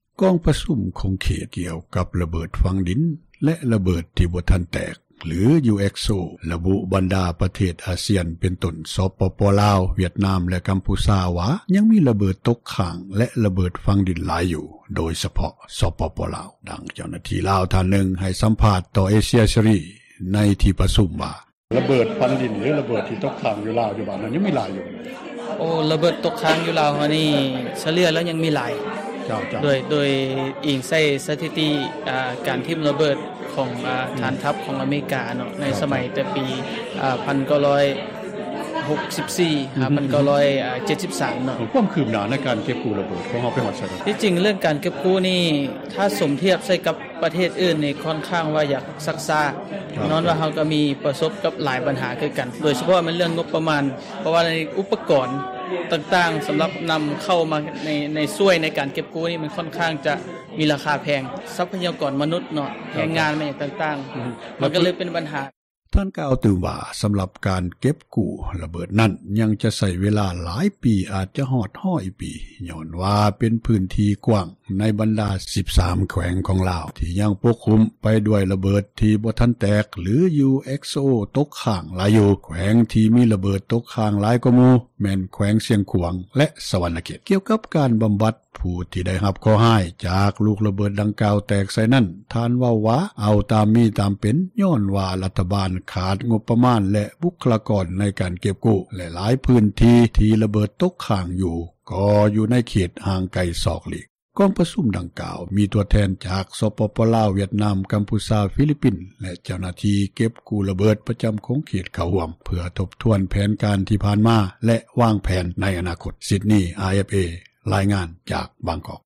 ເອເຊັຽເສຣີ ສໍາພາດ ຜູ້ເຂົ້າຮ່ວມ ເວທີຂົງເຂດ ກ່ຽວກັບ ຣະເບີດຝັງດິນ ແລະຣະເບີດທີ່ບໍ່ທັນແຕກ ທີຈັດຂຶ້ນຢູ່ບາງກອກ ປະເທດໄທ